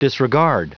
Prononciation du mot disregard en anglais (fichier audio)
Prononciation du mot : disregard